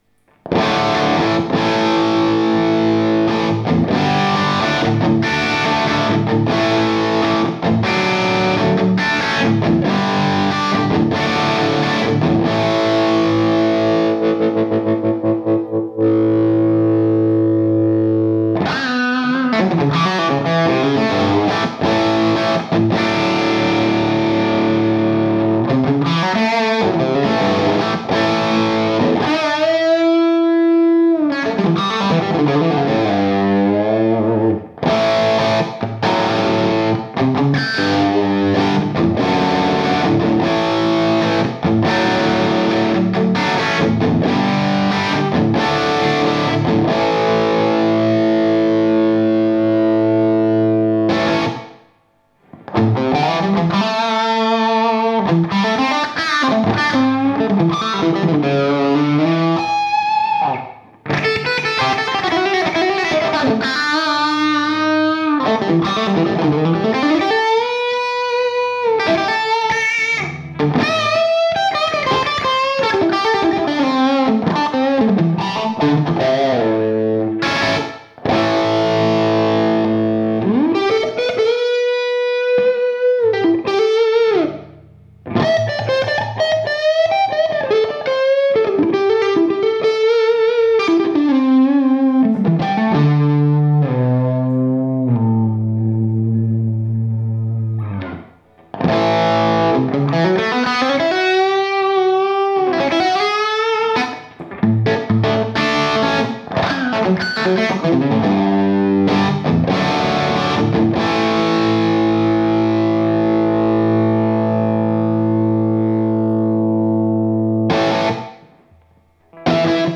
J'ai pris le Trem-O-Verb, le CAB NOS 4x12 V30 et ajusté les réglages.
Donc j'ai baissé un chtouille le gain.
Quatrième micro, un AT4050 en figure de 8 loin (2.70m), orienté plutôt à 45°pour que l'onde directe du cab ne soit pas captée, mais uniquement des réflexions.
Filtre passe haut sur AT4050 derrière le cab et léger creux
(vous entendrez les gouttes d'eau de la fontaine de mon chat .)